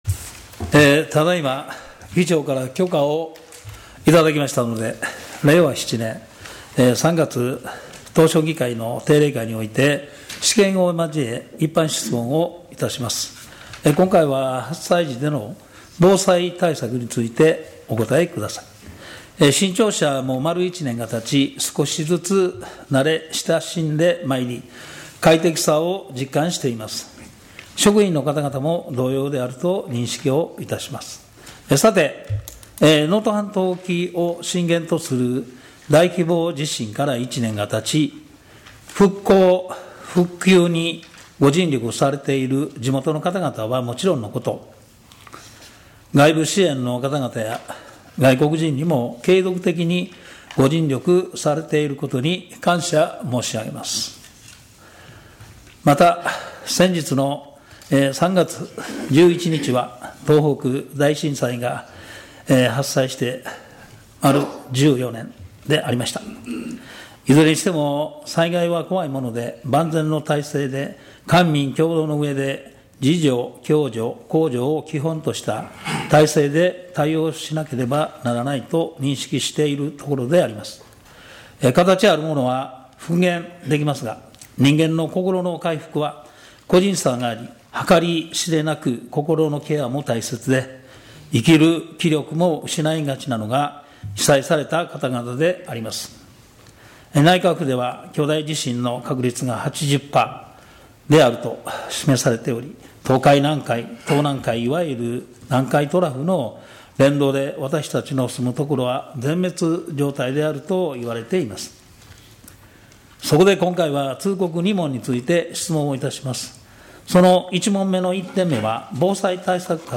一般質問
質問者：村上　宗隆議員